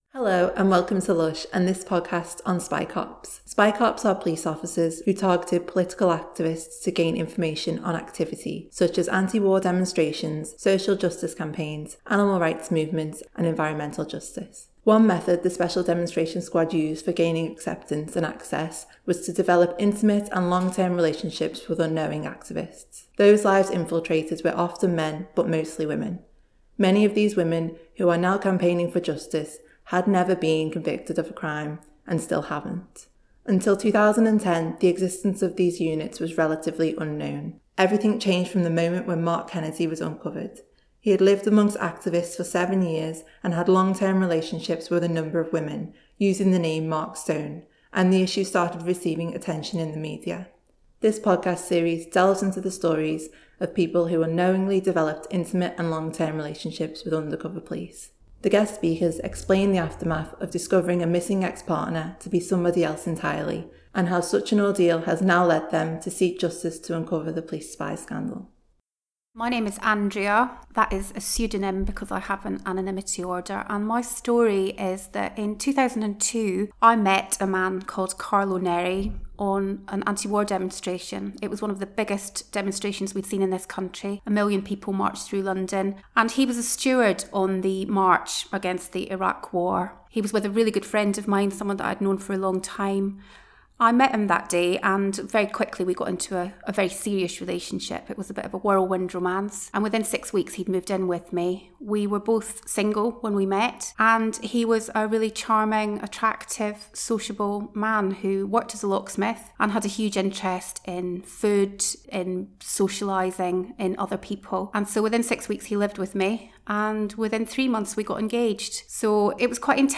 Many of these women, who are now campaigning for justice, had never been convicted of crime and still haven’t. This podcast series delves into the stories of people who unknowingly developed intimate and long term relationships with undercover police. The guest speakers explain the aftermath of discovering a missing ex-partner to be somebody else entirely, and how such an ordeal has led them to now seek justice to uncover the police spies scandal. https